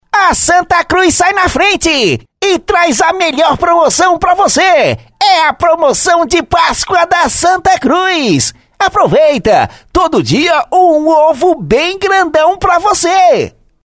Spot para rádio(Caricata):